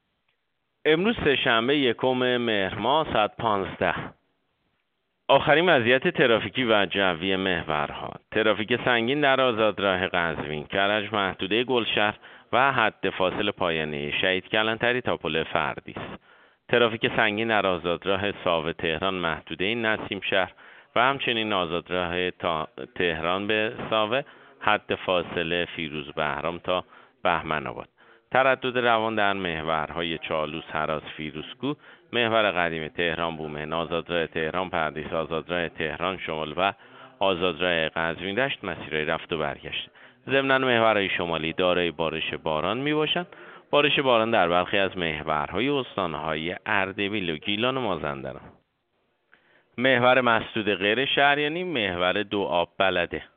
گزارش رادیو اینترنتی از آخرین وضعیت ترافیکی جاده‌ها ساعت ۱۵ یکم مهر؛